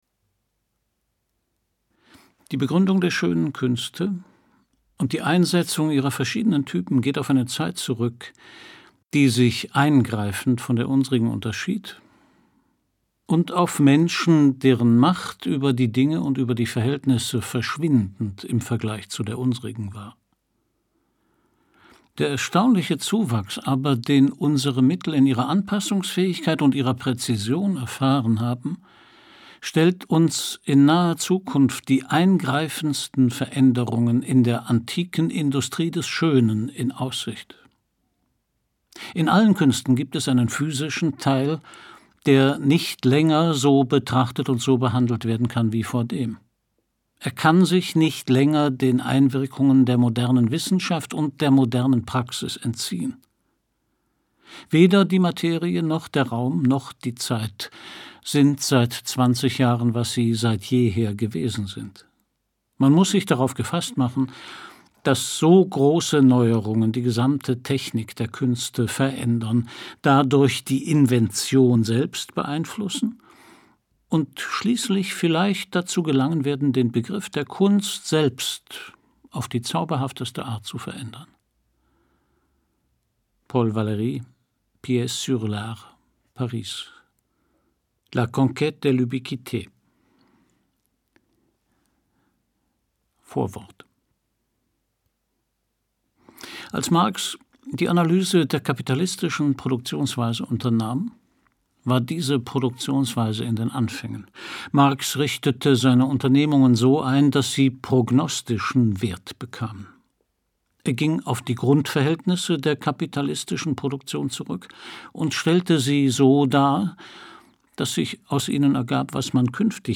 Christian Brückner (Sprecher)